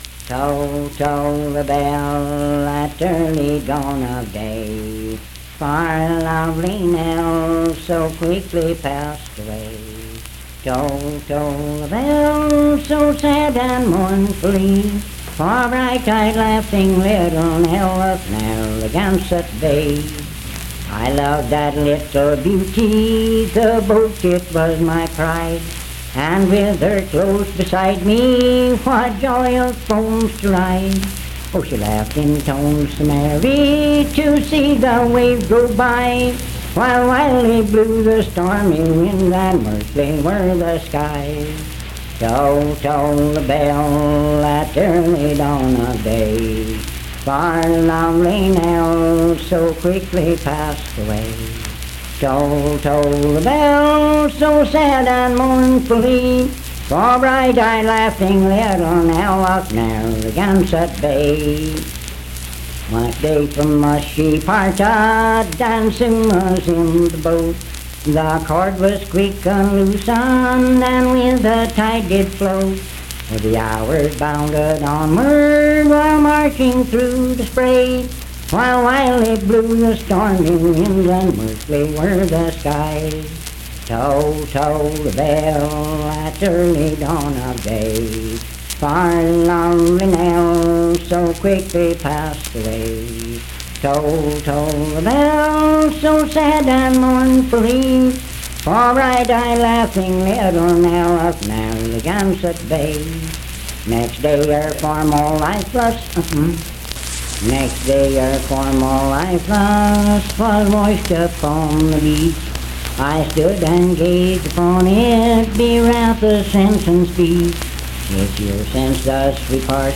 Unaccompanied vocal music
Performed at Sandyville, Jackson County, WV.
Voice (sung)